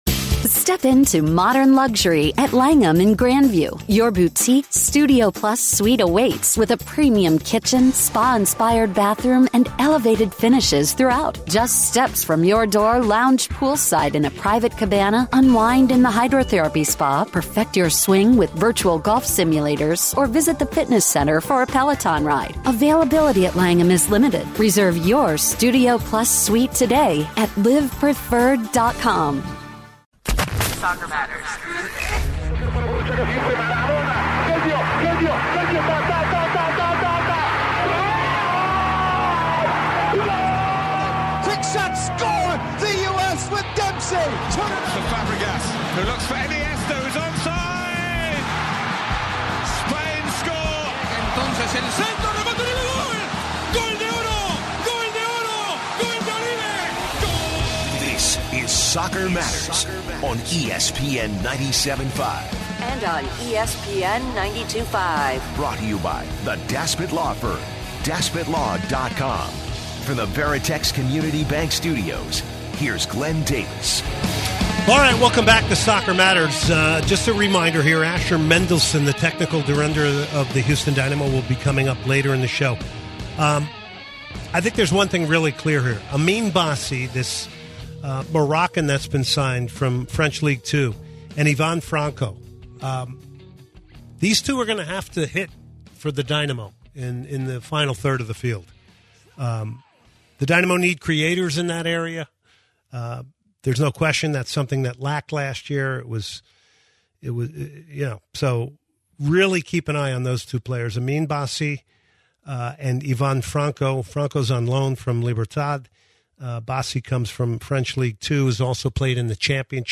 Former Stoke, QPR, and Houston Dynamo player Geoff Cameron joins Soccer Matters for an interview.